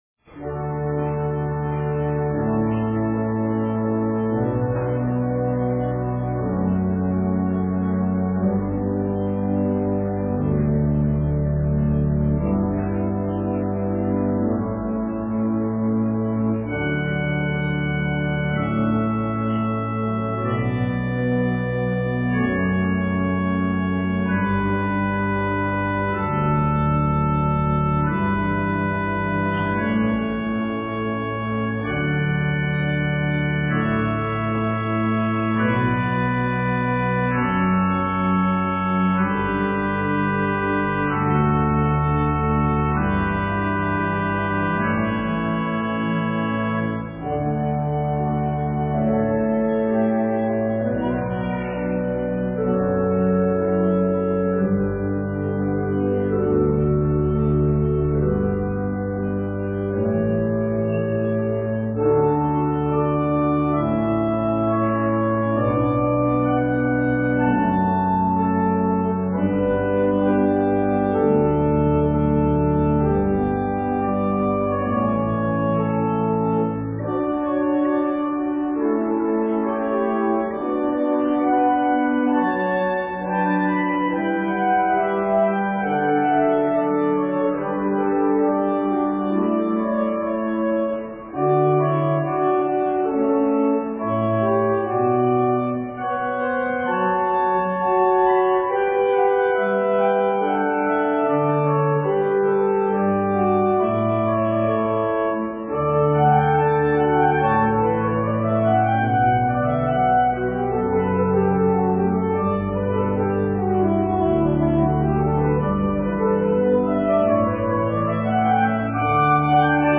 Piano Solo